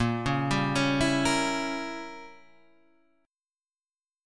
Bbdim7 chord